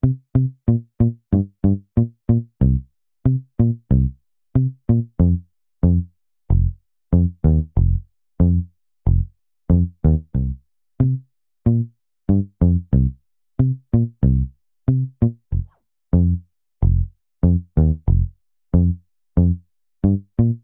08 bass B.wav